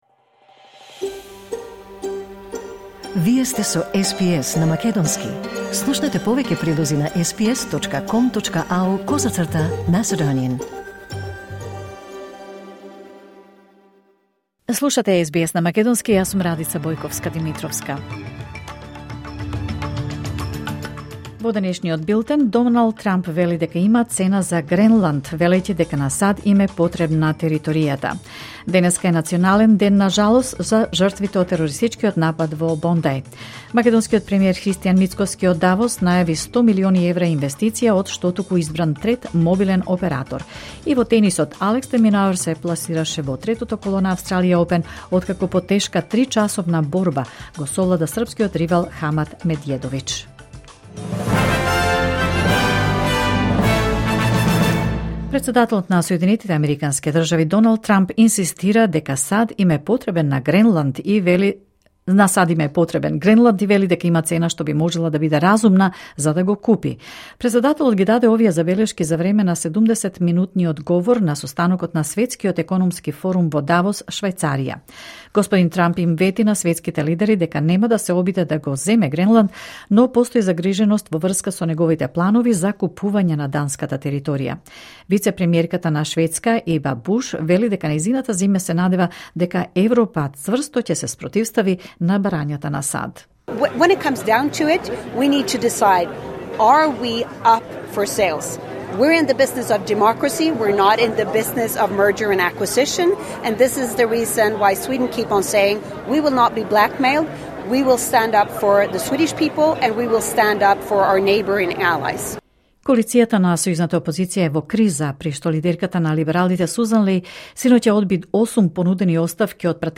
Вести на СБС на македонски 22 јанури 2026